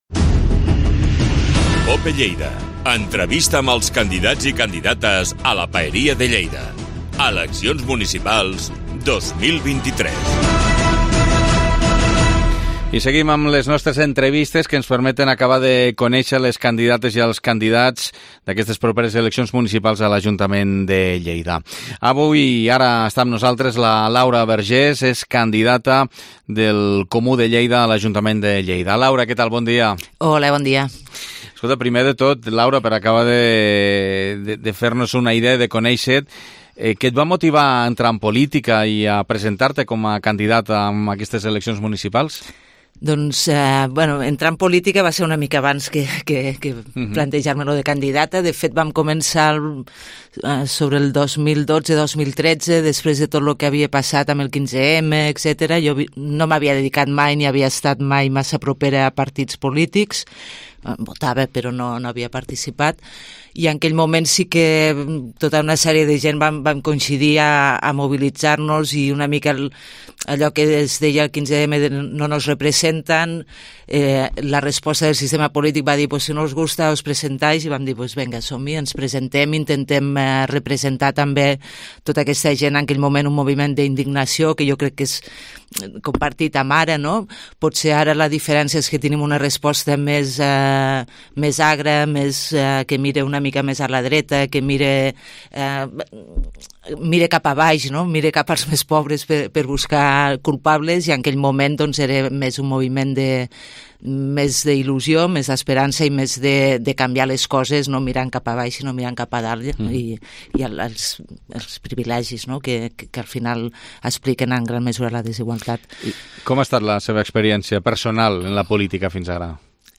Entrevista Campanya Electoral 2023